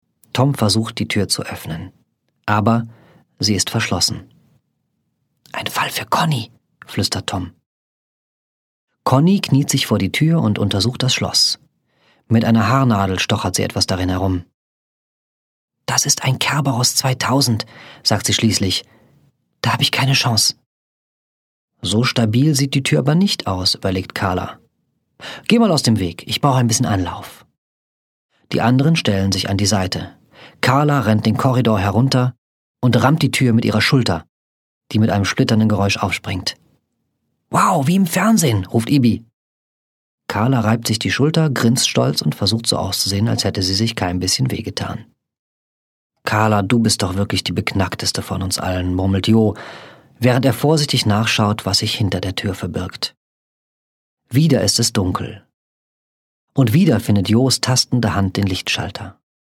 ✔ tiptoi® Hörbuch ab 7 Jahren ✔ Jetzt online herunterladen!
Jeck-Hoerprobe.mp3